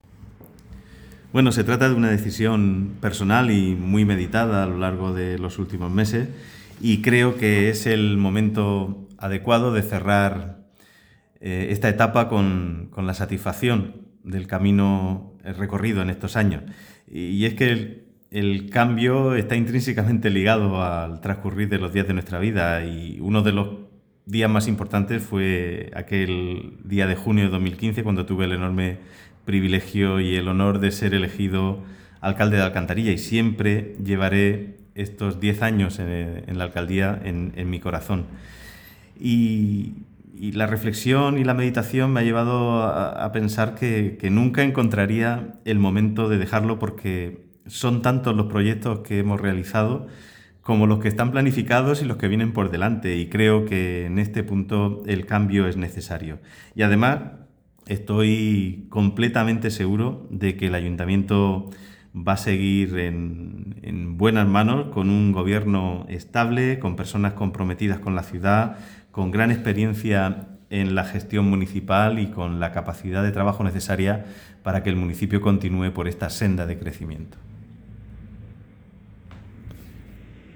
Declaraciones de Joaquín Buendía, alcalde de Alcantarilla.